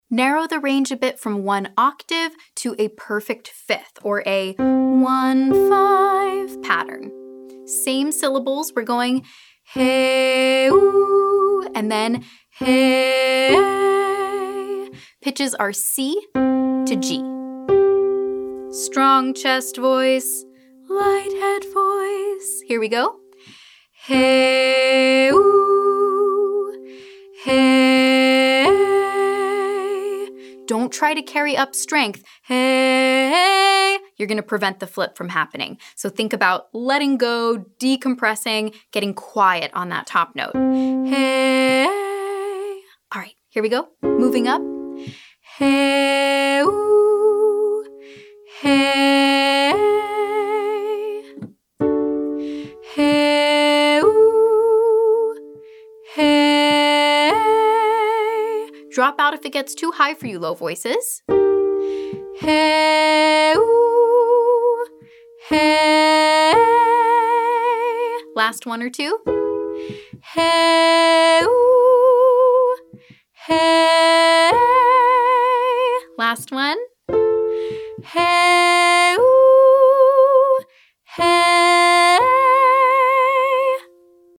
It’s an abrupt transition from chest voice to head voice, resulting in something of a yodel effect.
• HEY-OO, HE-EY 1-5